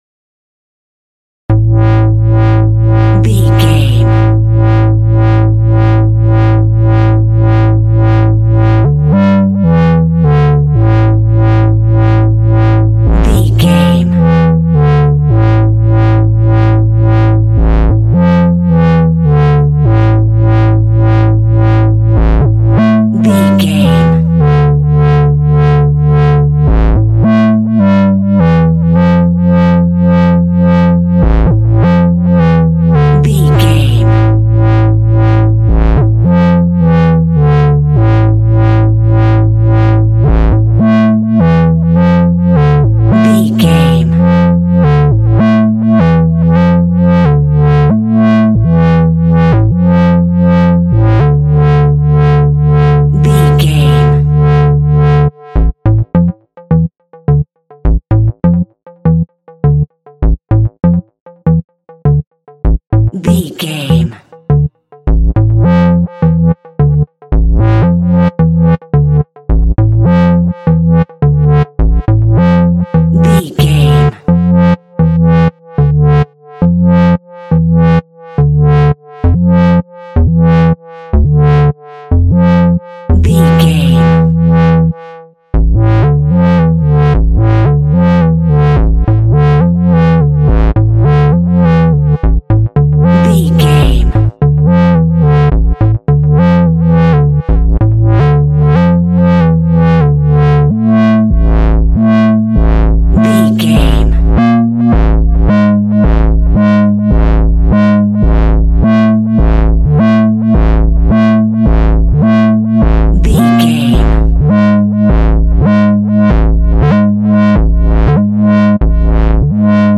Aeolian/Minor
tension
ominous
haunting
eerie
Horror synth
Horror Ambience
synthesizer